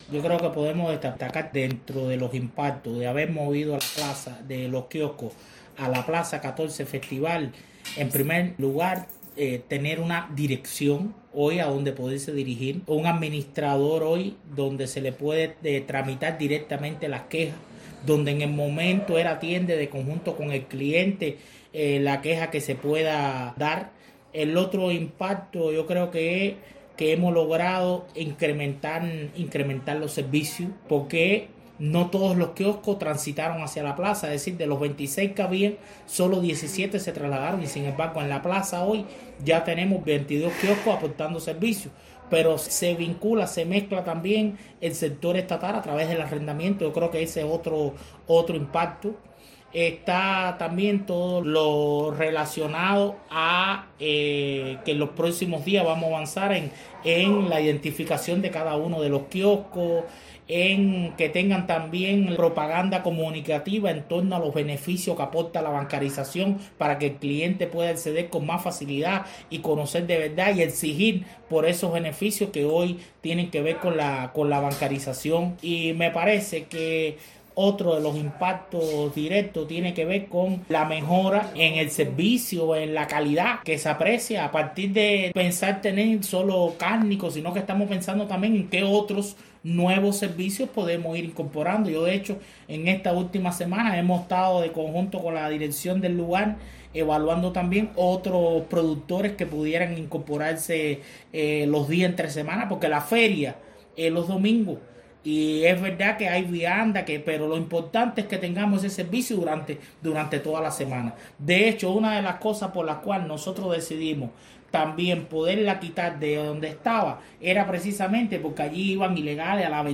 También el intendente del municipio de Matanzas, Anselmo Díaz Muñiz ofreció declaraciones a esta redacción sobre los impactos y retos que se aprecian tras el traslado de los quioscos de las mipymes hacia la XIV Festival: